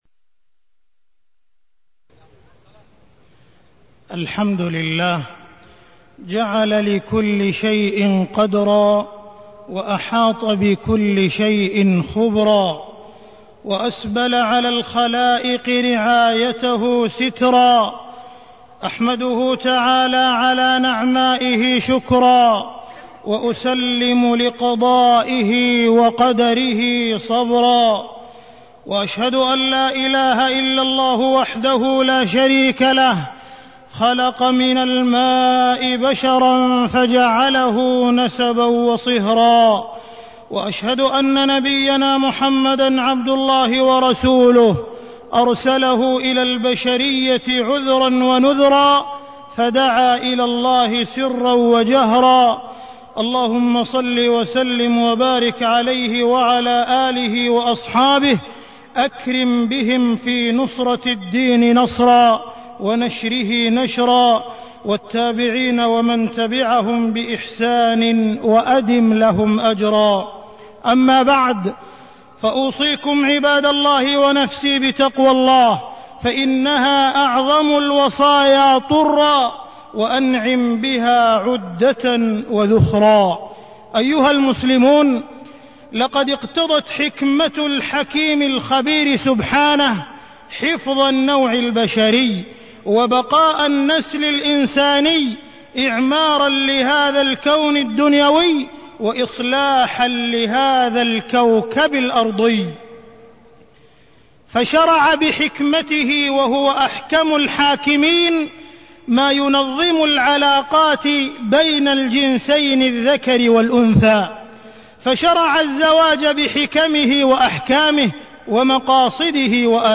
تاريخ النشر ٨ ربيع الثاني ١٤٢٢ هـ المكان: المسجد الحرام الشيخ: معالي الشيخ أ.د. عبدالرحمن بن عبدالعزيز السديس معالي الشيخ أ.د. عبدالرحمن بن عبدالعزيز السديس الحكمة من مشروعية الزواج